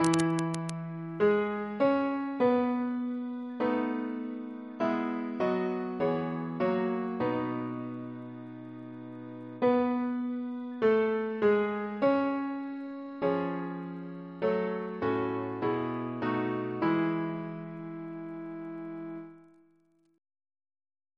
Double chant in E Composer: Sir Joseph Barnby (1838-1896), Precentor of Eton, Principal of the Guildhall School of Music Reference psalters: ACB: 73; ACP: 350; CWP: 22; H1940: 632; RSCM: 16